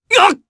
Clause-Vox_Damage_jp_03.wav